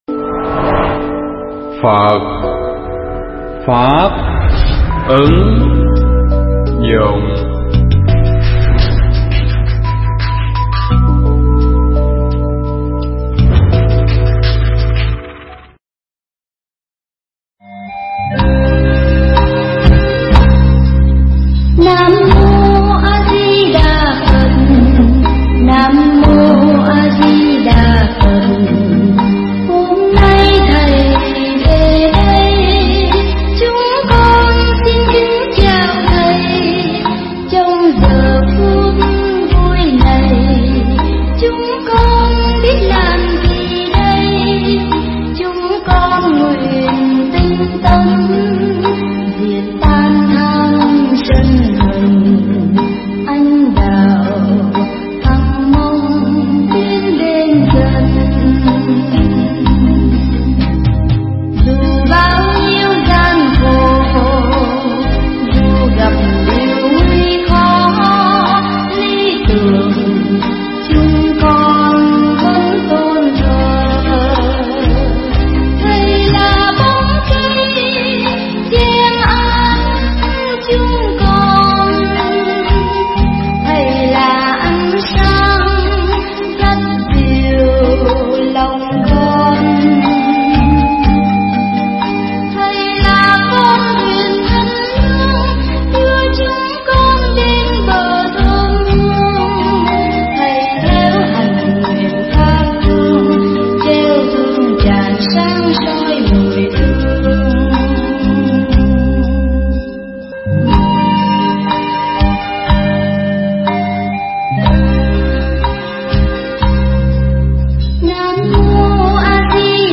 pháp âm Đạo Lý Thầy Trò
thuyết giảng tại chùa Giác Ngộ